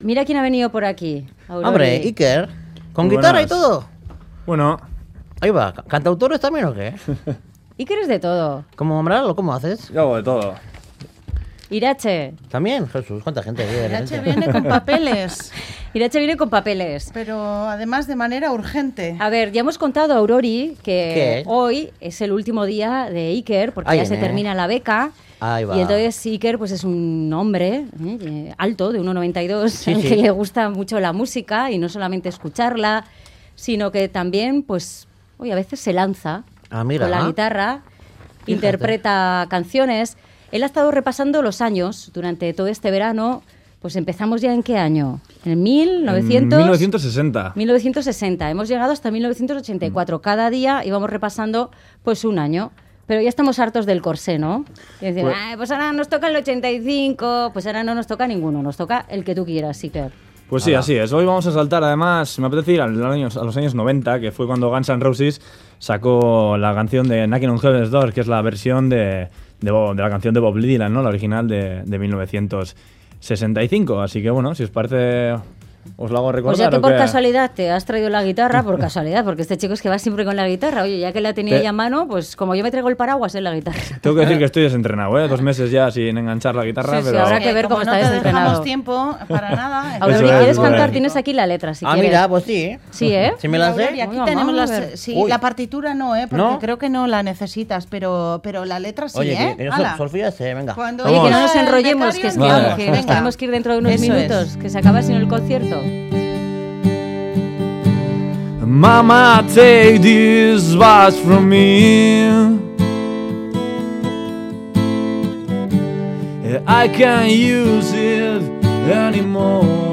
guitarra en mano
interpretando en directo varias canciones